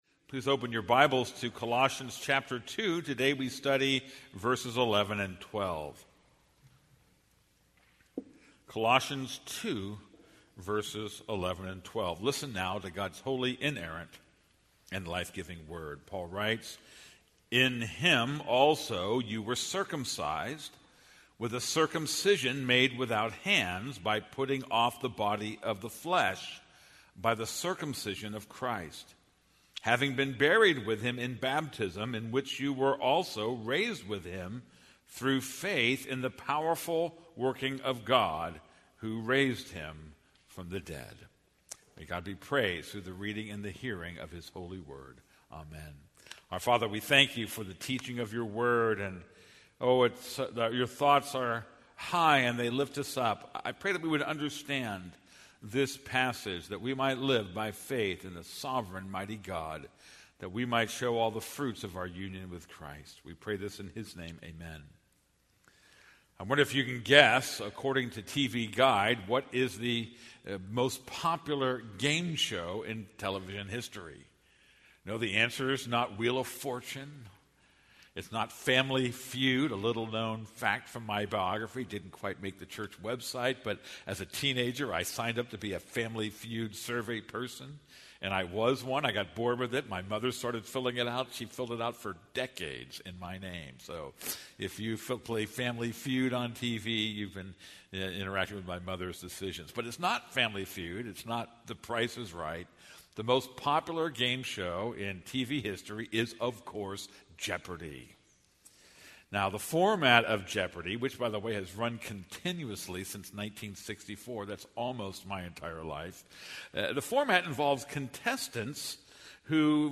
This is a sermon on Colossians 2:11-12.